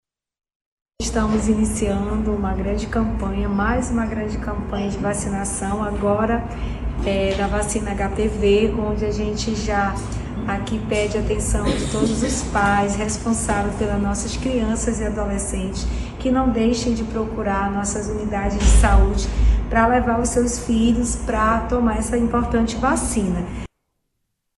A prefeita, Socorro Nogueira, destaca a importância dos pais para o êxito da campanha.